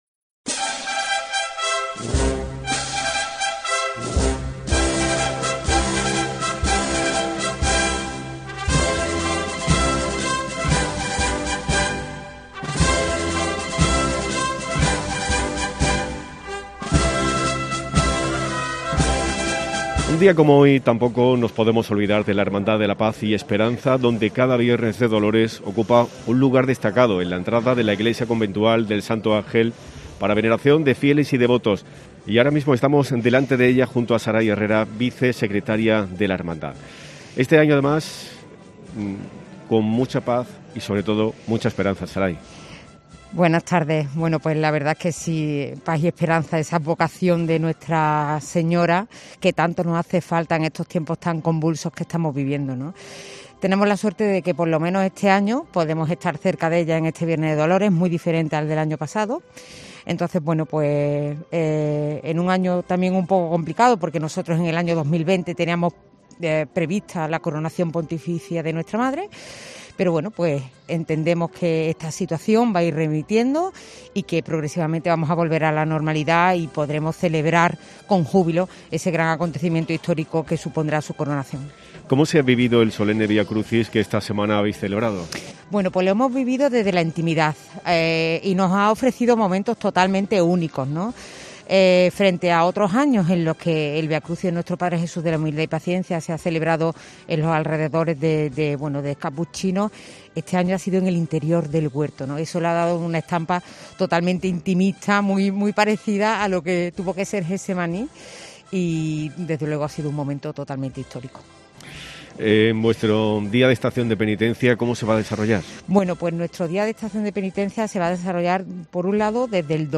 Cada viernes de Dolores, COPE se traslada a la plaza de capuchinos, al epicentro de la fe.